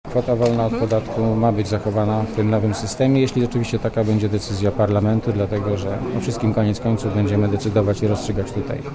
Mówi Grzegorz Kołodko (70Kb)